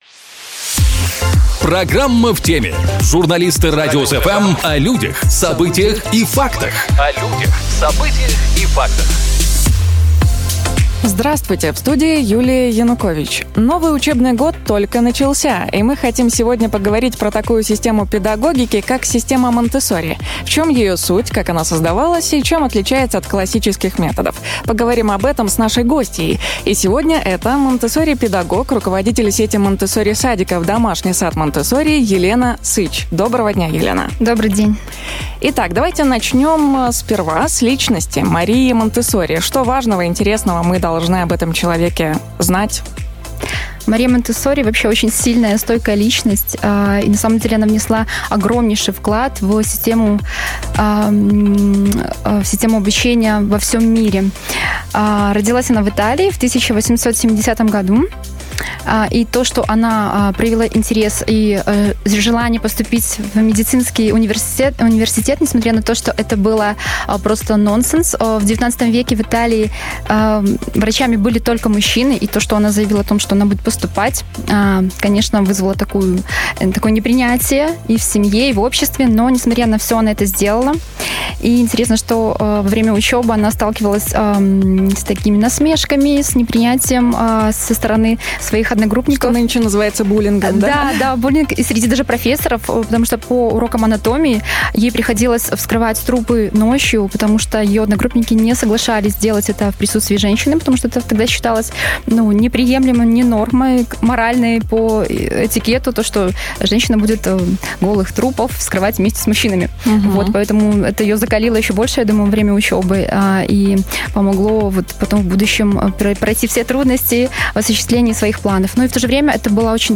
У нас в гостях – Монтессори-педагог